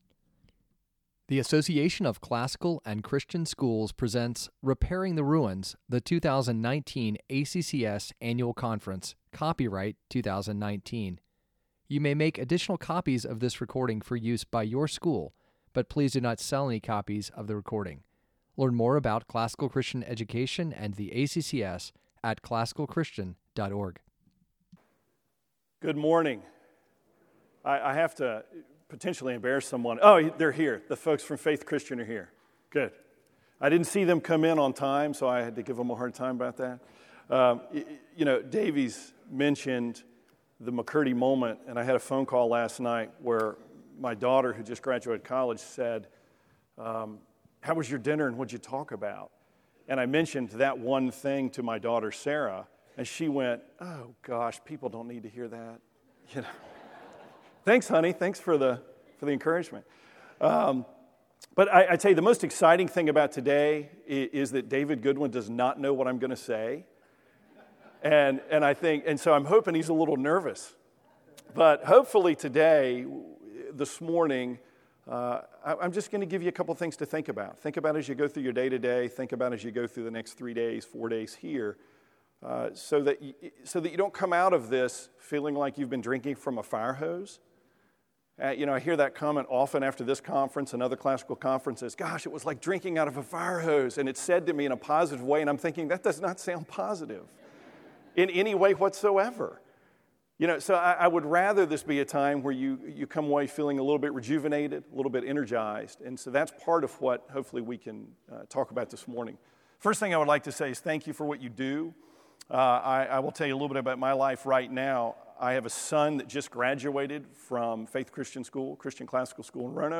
2019 Leaders Day Talk | 48:57 | Leadership & Strategic, Virtue, Character, Discipline